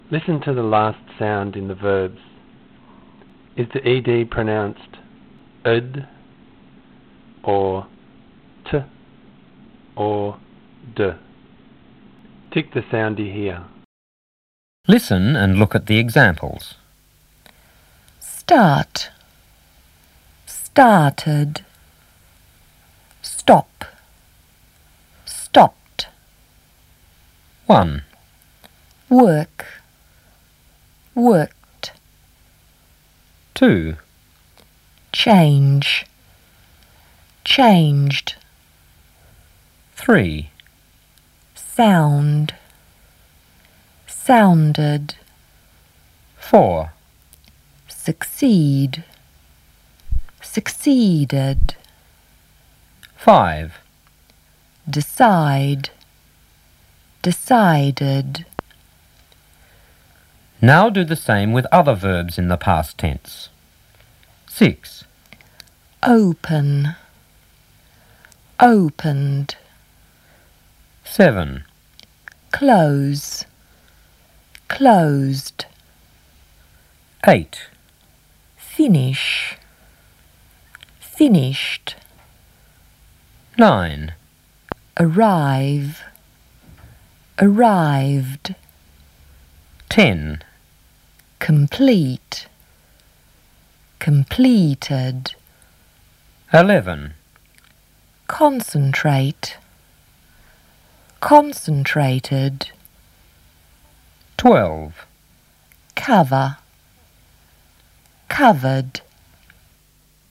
Pronouncing regular verbs